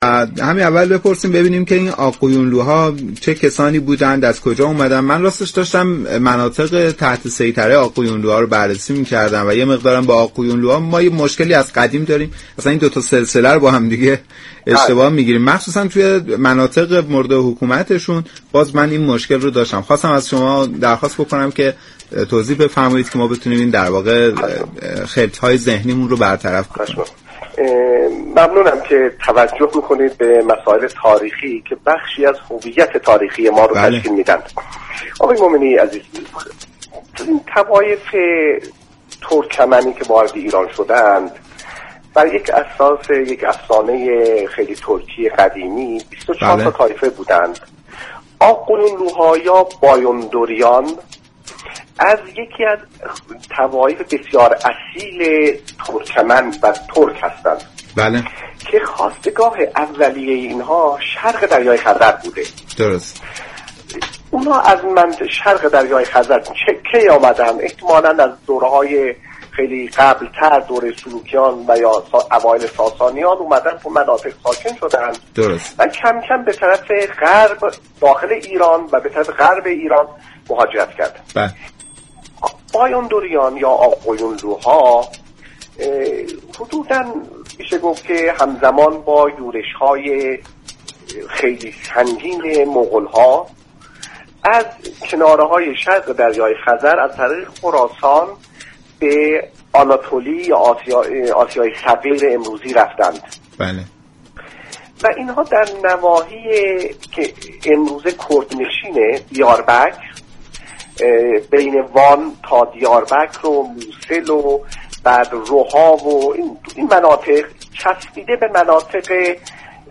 فایل صوتی مربوط به این گفتگو را در سایت رادیو فرهنگ بشنوید .